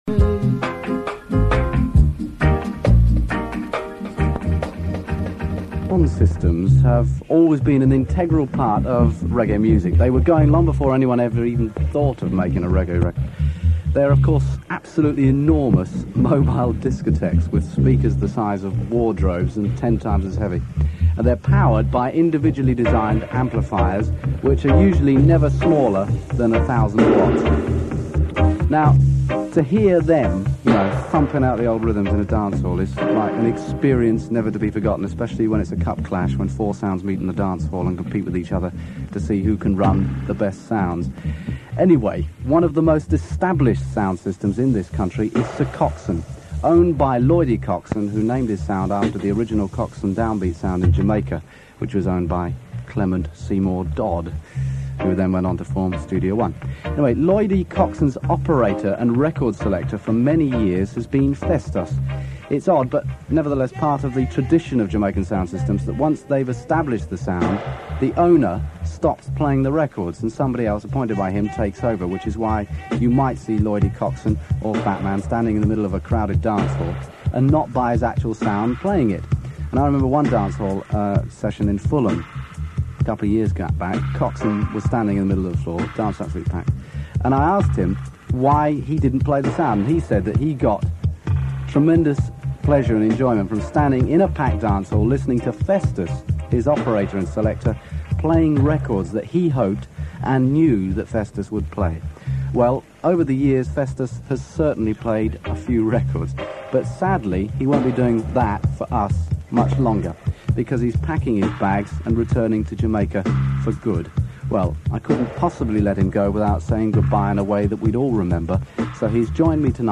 Re-up of this historical radio show from the early 80's